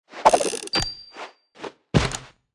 Media:Sfx_Anim_Ultimate_Colt.wav 动作音效 anim 在广场点击初级、经典、高手、顶尖和终极形态或者查看其技能时触发动作的音效
Sfx_Anim_Ultra_Colt.wav